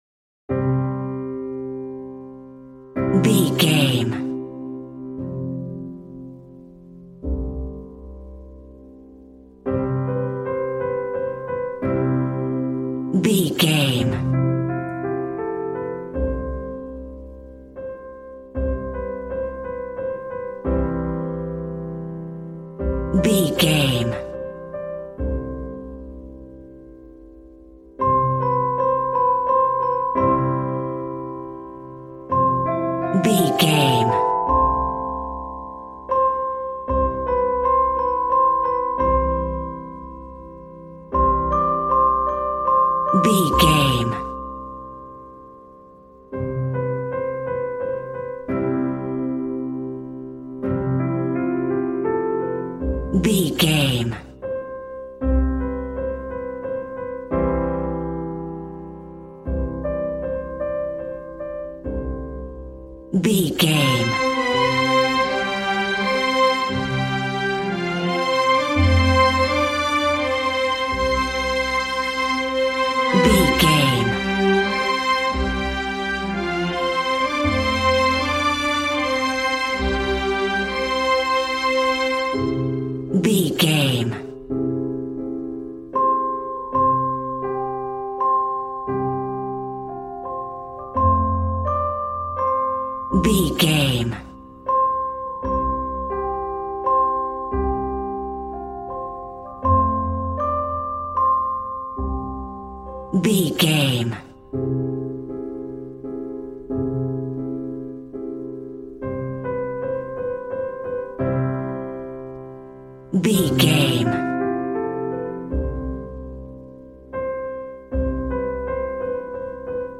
Regal and romantic, a classy piece of classical music.
Ionian/Major
regal
strings
violin